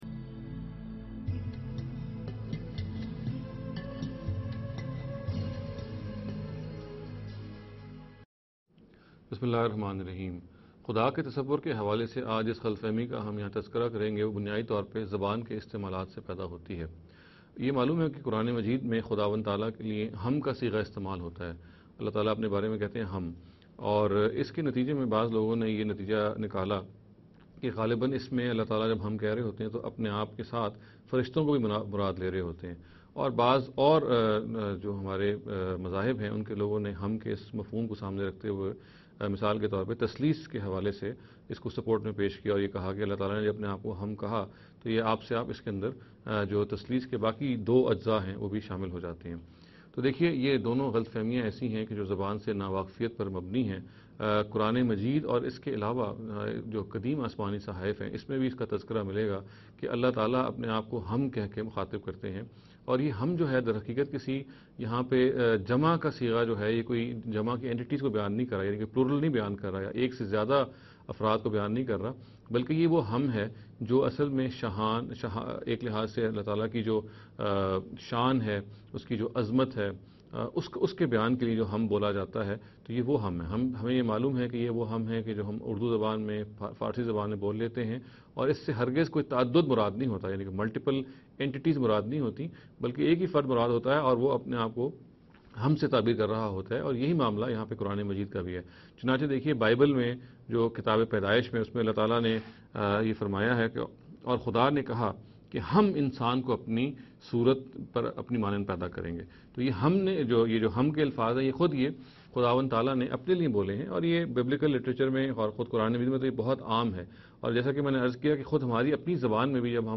This lecture series will deal with some misconception regarding the Concept of God.